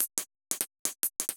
Index of /musicradar/ultimate-hihat-samples/175bpm
UHH_ElectroHatA_175-03.wav